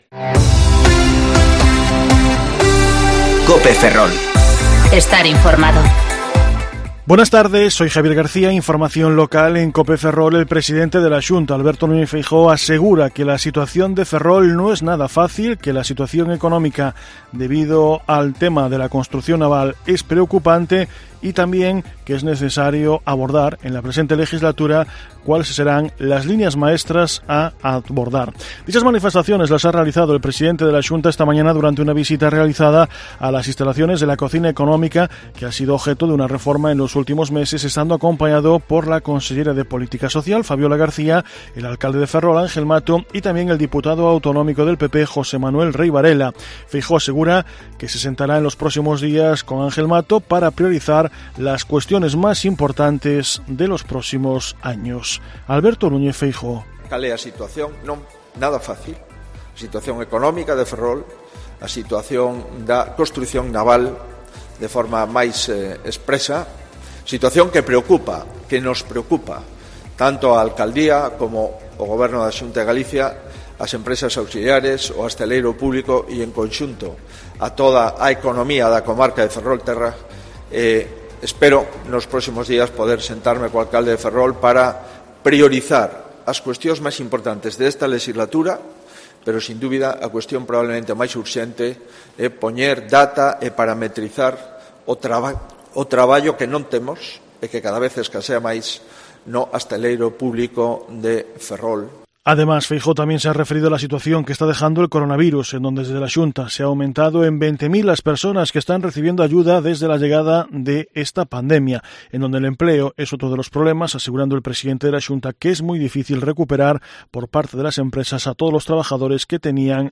Informativo Mediodía COPE Ferrol 5/10/2020 (De 14,20 a 14,30 horas)